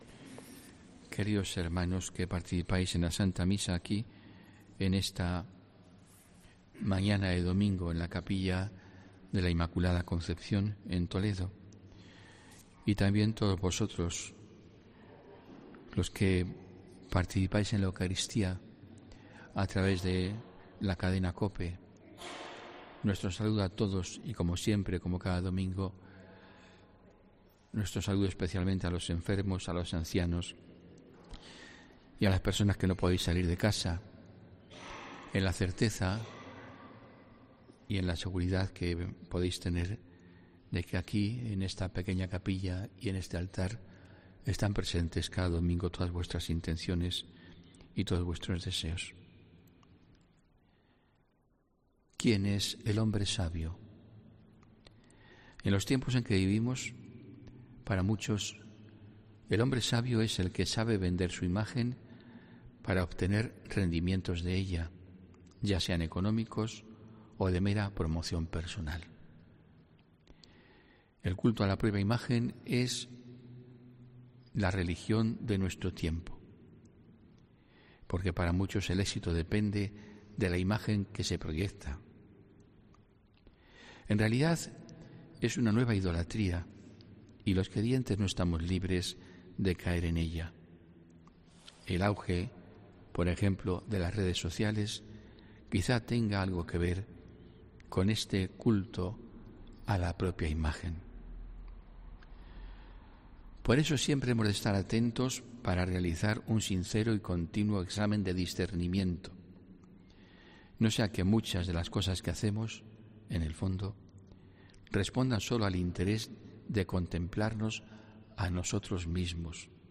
HOMILÍA 10M OCTUBRE 2021